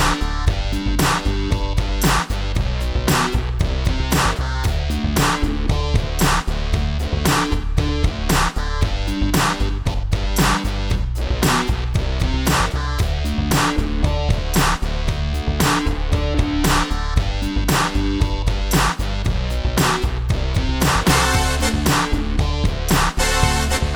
No Main Guitar Rock 4:27 Buy £1.50